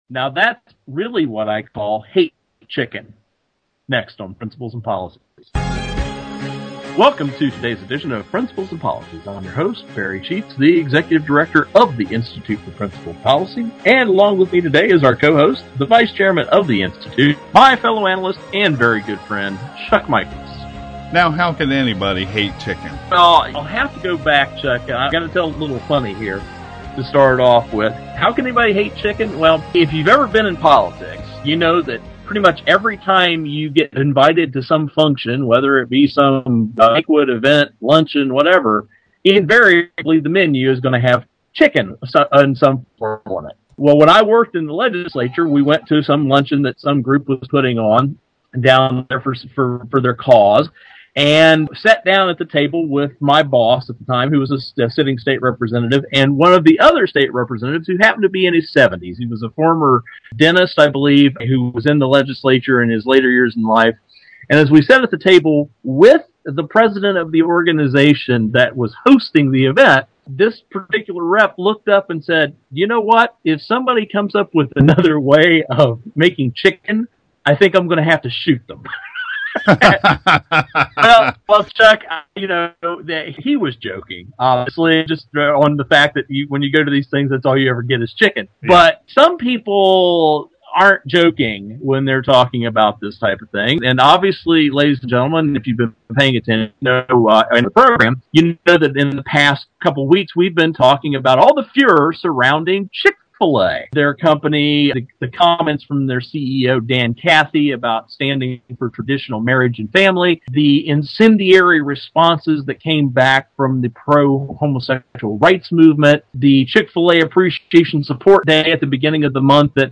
Our Principles and Policies radio show for Saturday August 18, 2012.